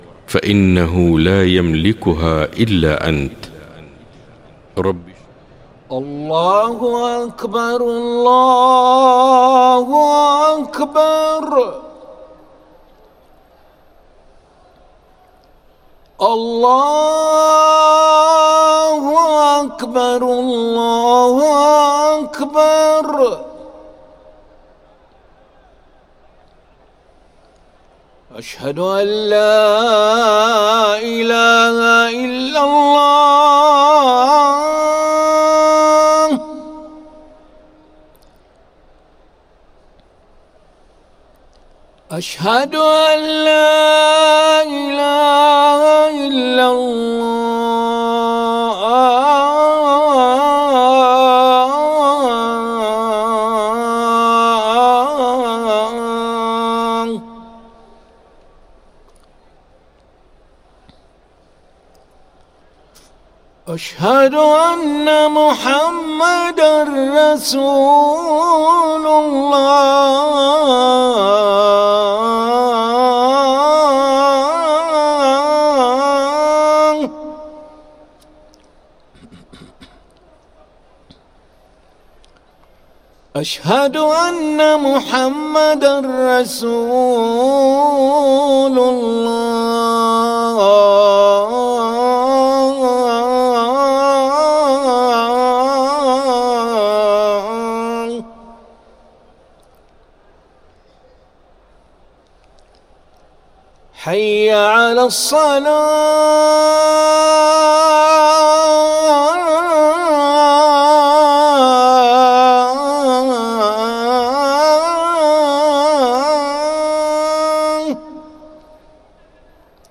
أذان العشاء للمؤذن علي ملا الأحد 14 ذو الحجة 1444هـ > ١٤٤٤ 🕋 > ركن الأذان 🕋 > المزيد - تلاوات الحرمين